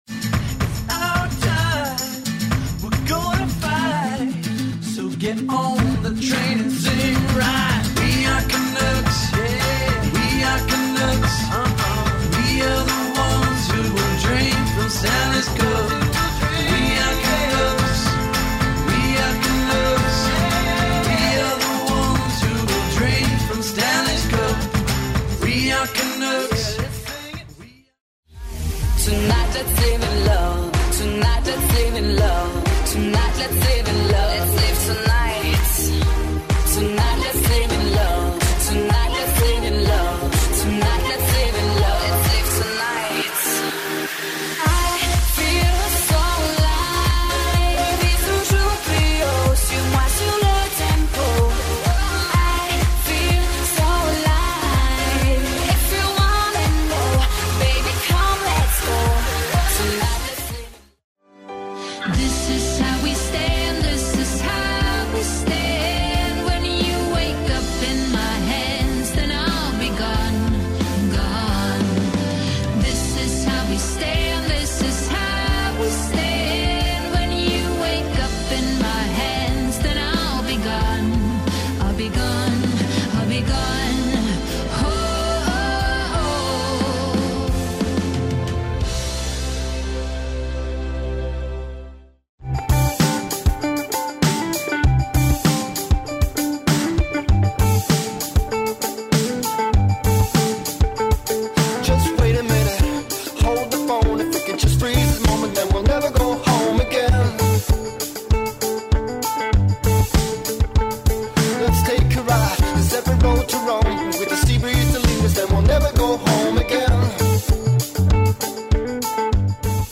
styl - pop rock/world music/dance/chillout